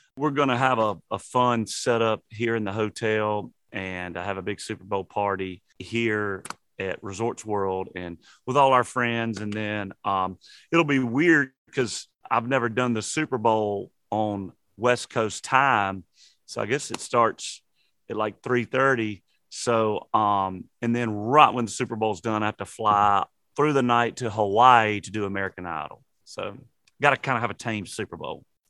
Audio / Luke Bryan says he and his family and friends will have a Super Bowl party at Resorts World since he’s in Sin City for opening weekend his Las Vegas residency.